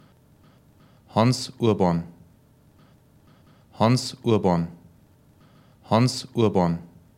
Wie spricht man eigentlich den Namen richtig aus,